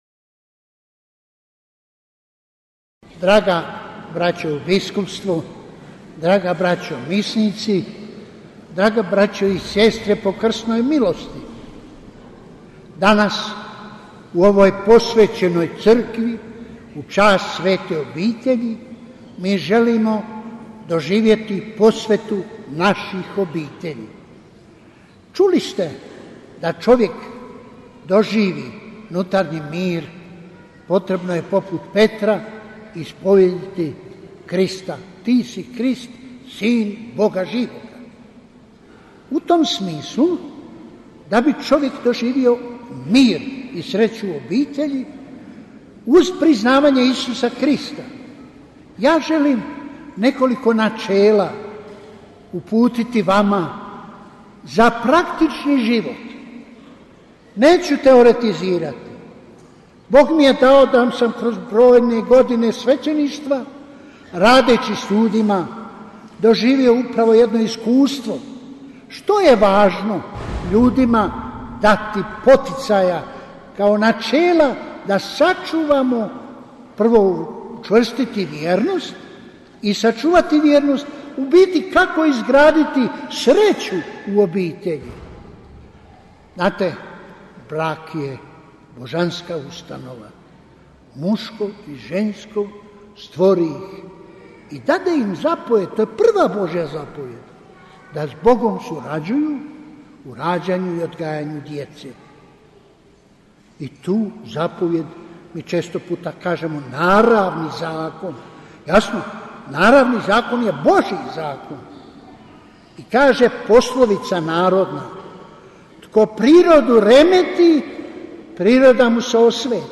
PROPOVIJED KARDINALA PULJIĆA NA MISI U OKVIRU DRUGOG OBITELJSKOG DANA U BIH - BANJOLUČKA BISKUPIJA
Drugi Obiteljski dan u Bosni i Hercegovini, koji je organiziralo Vijeće za obitelj Biskupske konferencije Bosne i Hercegovine uz suorganizaciju župe Svete Obitelji u Kupresu i Udruge Kupreški kosci, svečano je proslavljen u nedjelju, 28. srpnja 2019. u župnoj crkvi Svete Obitelji u Kupresu. Euharistijsko slavlje predvodio je predsjednik Biskupske konferencije BiH kardinal Vinko Puljić, nadbiskup metropolit vrhbosanski.